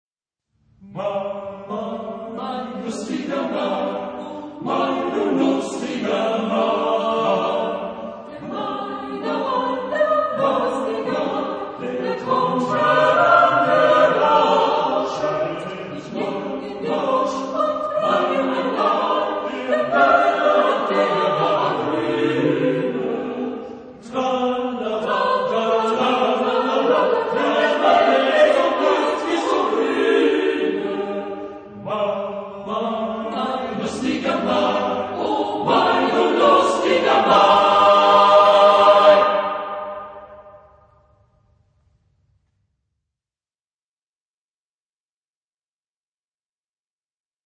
Genre-Style-Form: Folk music ; Madrigal ; Secular
Type of Choir: SATB  (4 mixed voices )
Tonality: G major